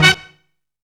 DO IT HIT.wav